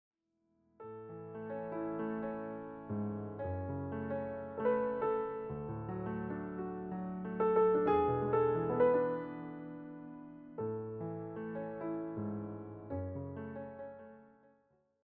presented as relaxed piano interpretations.